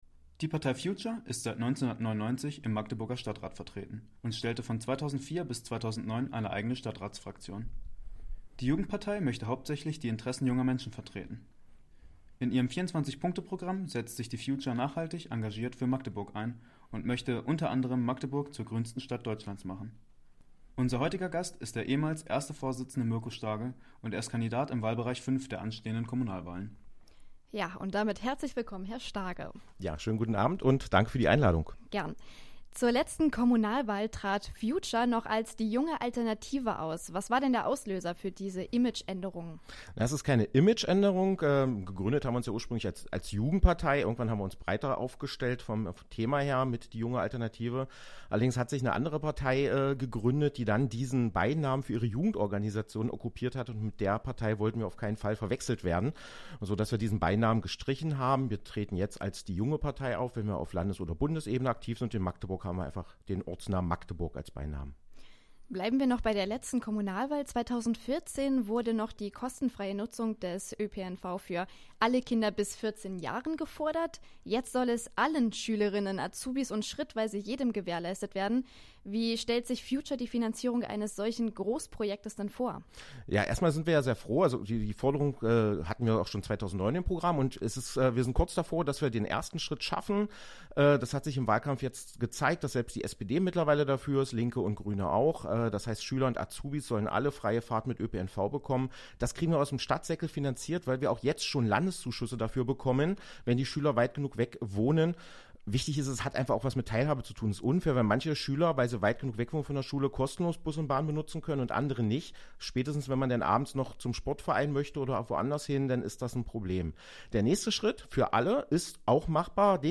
Live-Interview